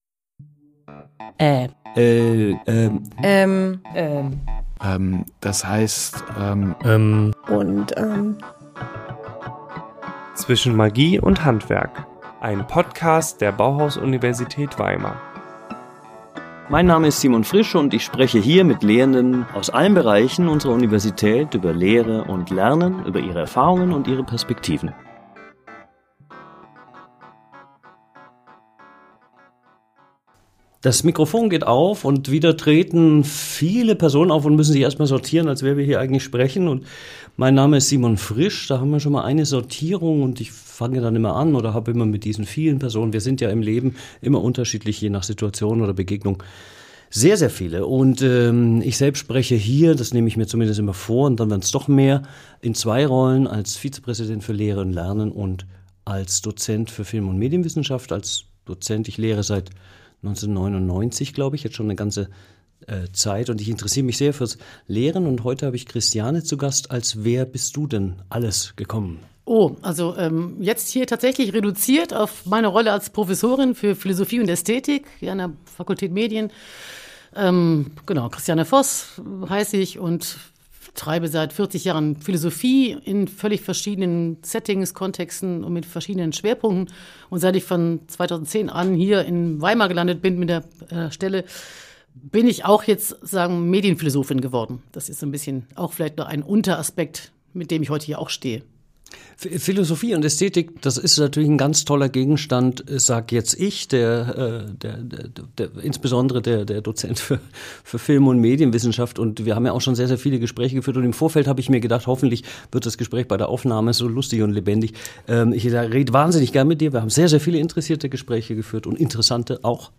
Gast